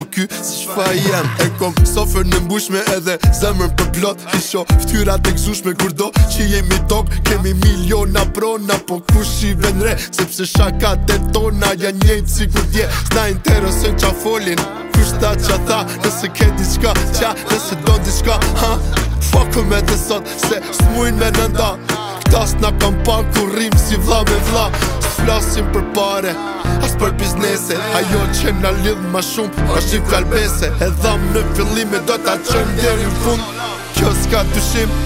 Kategorien: Rap/Hip Hop